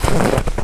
STEPS Snow, Walk 20.wav